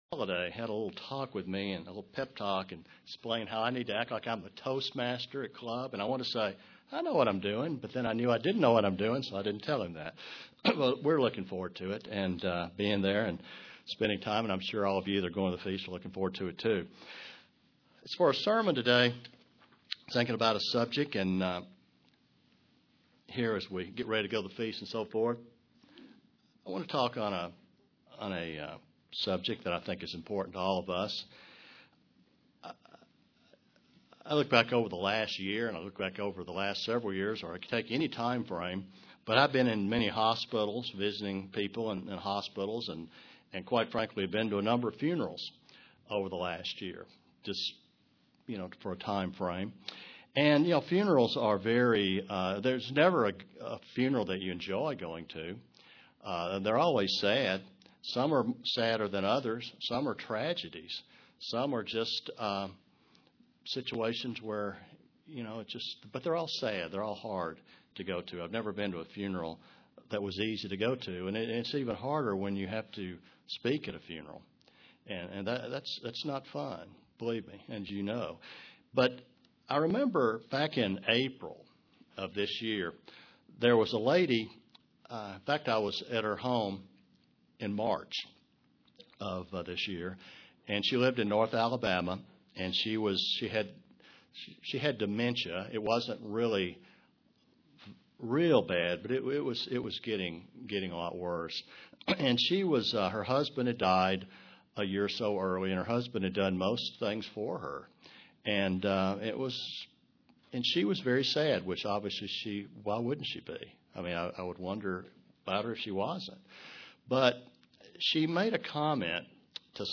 Print Being a Christian requires the armor of God as a soldier UCG Sermon Studying the bible?